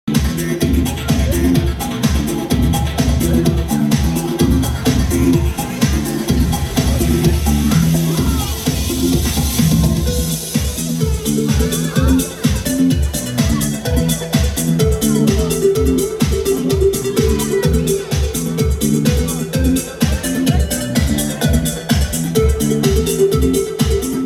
Tytuł piosenki, wykonawcy utworu muz. z Turcji - Muzyka elektroniczna
Podkład muzyczny do wyświetlanego krótkiego filmiku o atrakcjach turystycznych Turcji.